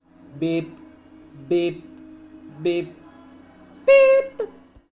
音频1 " 01哔哔声巩固的声音
声道立体声